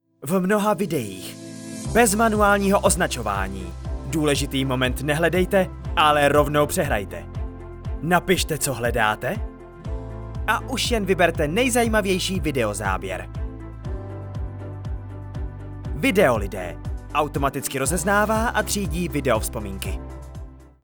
Profesionální voice-over pro vaše video
• samotné studiová nahrávka a případný mix dodaného hudebního podkladu.